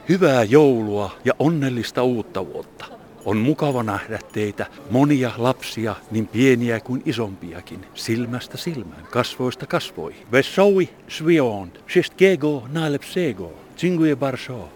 Na Gdańskim lotnisku wylądował wyjątkowy gość z dalekiej Laponii.